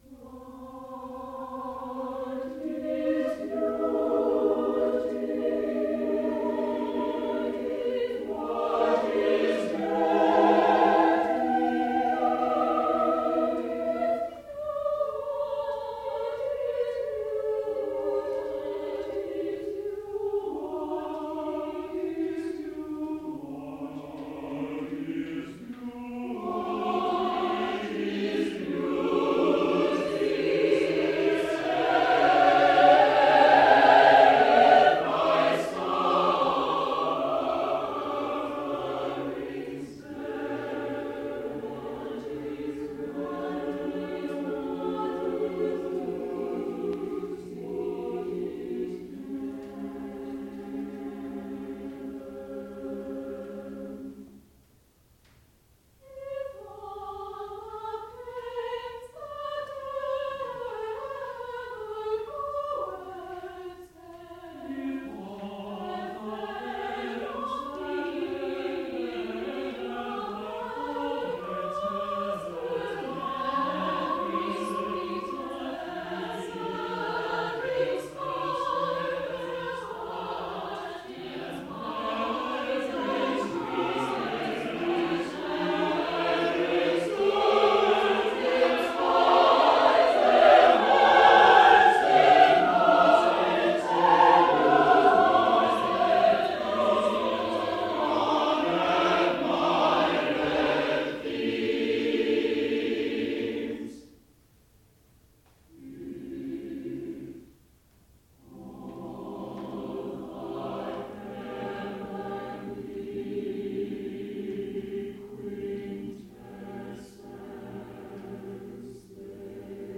for SSATB chorus